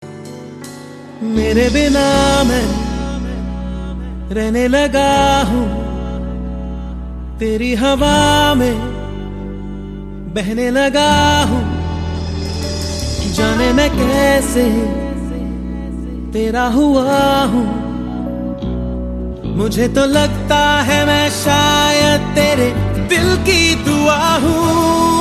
File Type : Mp3 ringtones